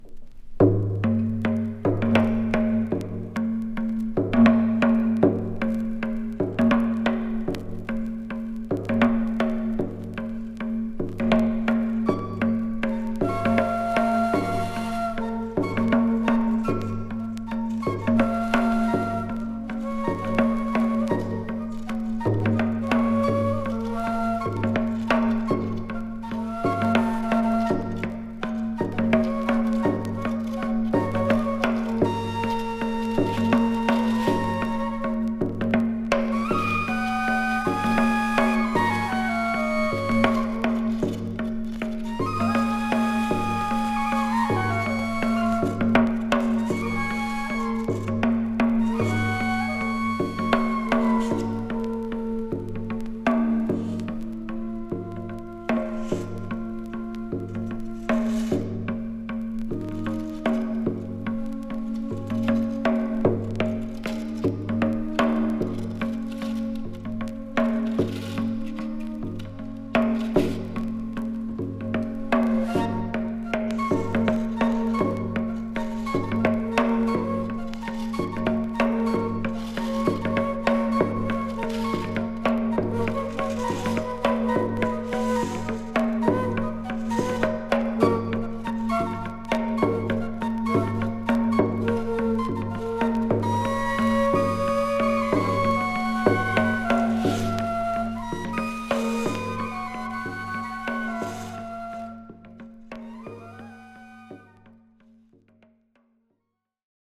フォルクローレに世界各地の民族楽器の響きが木霊する圧巻の名腰！